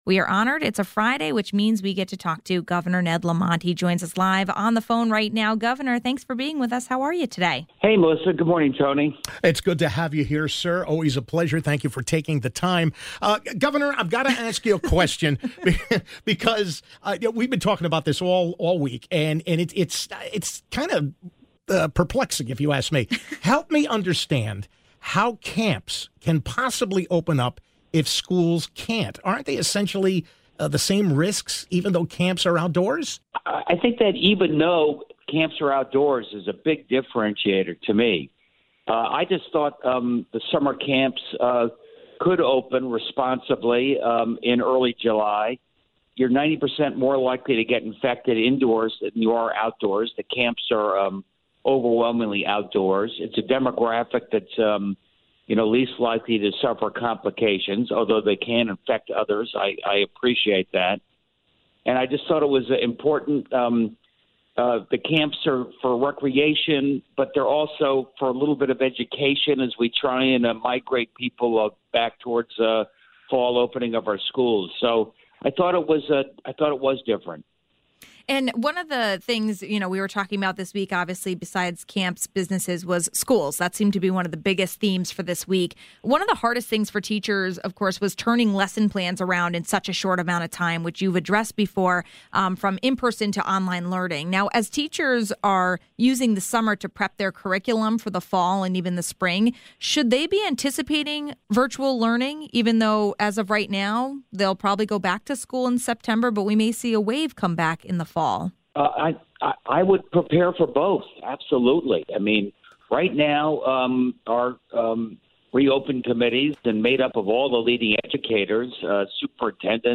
Governor Lamont spends time on the show answering your questions. This week, some big announcements were made for schools, college, businesses and camps. We talk about that as well as testing capacity in the state.